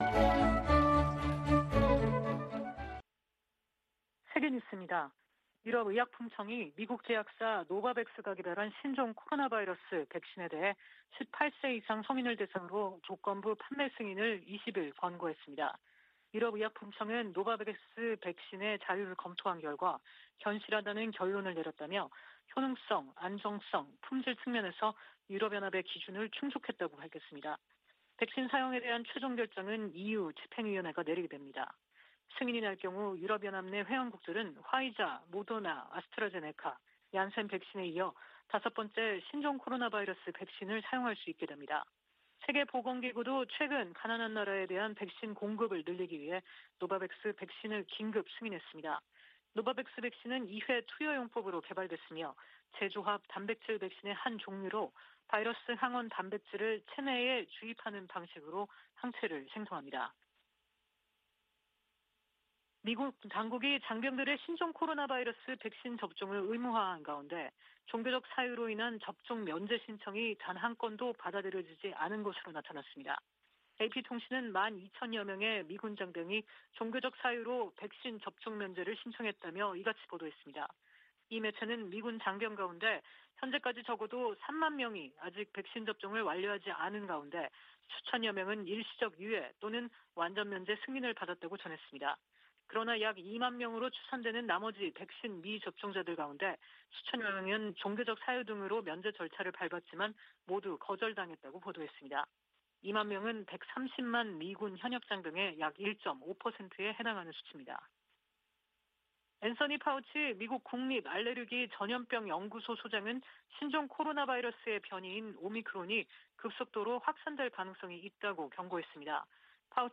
VOA 한국어 아침 뉴스 프로그램 '워싱턴 뉴스 광장' 2021년 12월 21일 방송입니다. 조 바이든 미국 행정부의 대북정책은 전임 두 행정부 정책의 중간이라는 점을 제이크 설리번 국가안보보좌관이 거듭 확인했습니다. 미 국방부는 최근 상원을 통과한 2022 회계연도 국방수권법안(NDAA)과 관련해 미한 동맹태세를 변경할 계획이 없다고 밝혔습니다. 북한이 내년 잠수함발사탄도미사일(SLBM)을 실전 배치할 것으로 예상된다는 보고서가 나왔습니다.